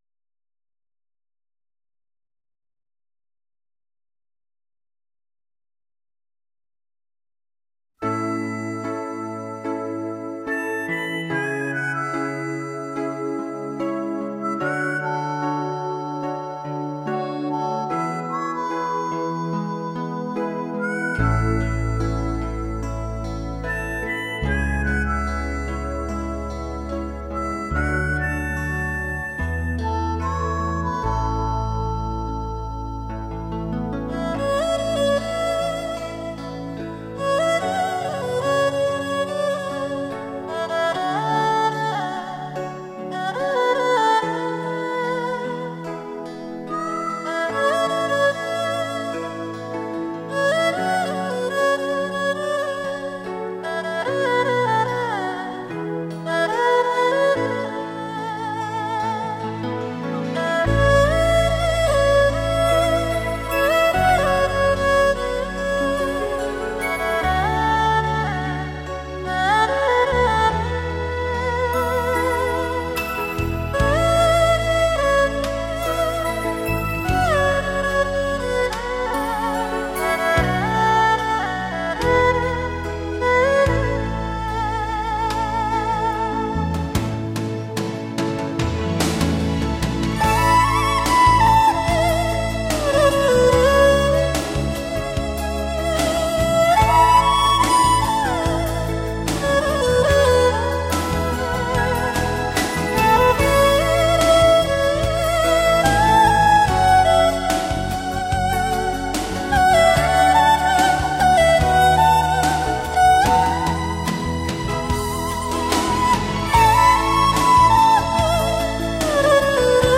二胡演奏
萨克斯独奏
吉它伴奏
马头琴伴奏
小提琴伴奏
双簧管伴奏
长笛伴奏
小号伴奏
琵琶伴奏
古筝伴奏